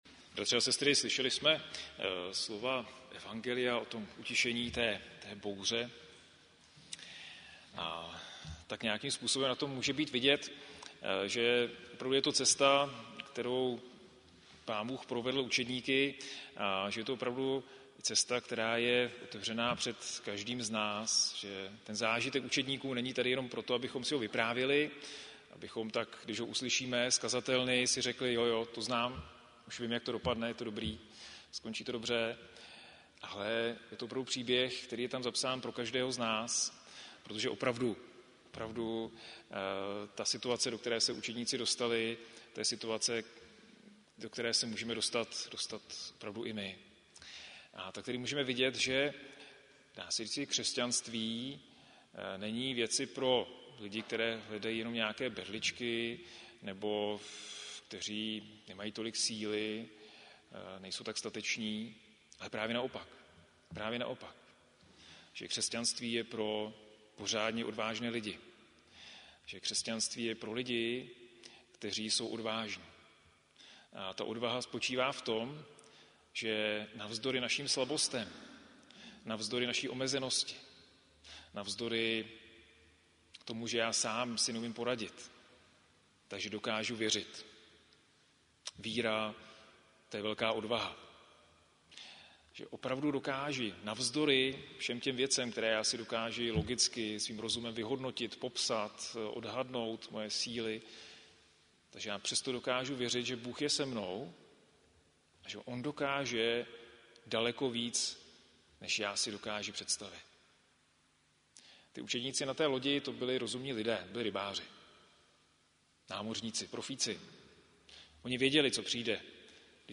záznam kázání